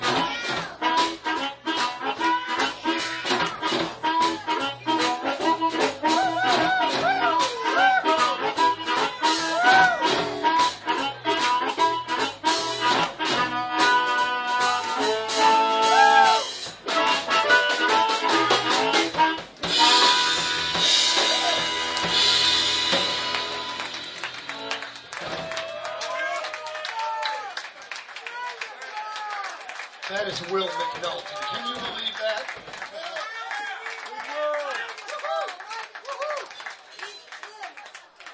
5th OTS Recital - Winter 2005 - rjt_4176